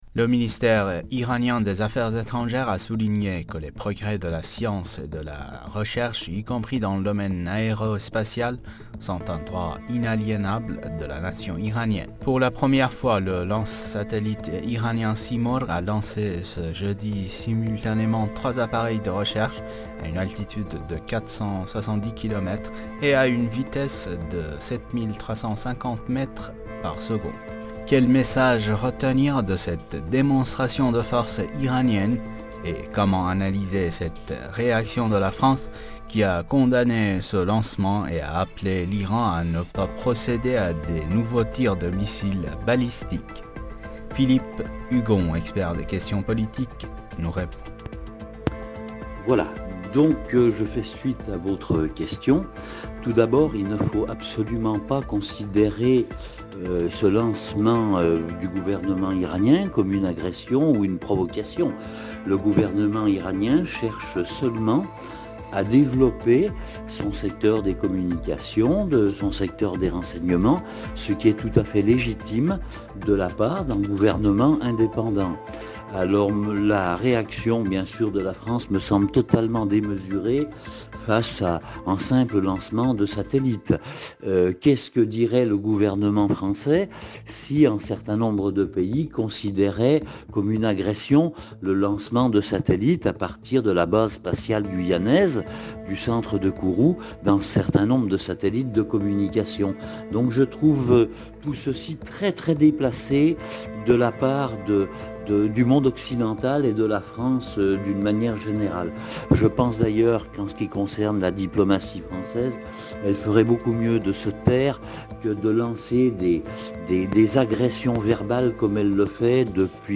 expert politique s'exprime sur le sujet.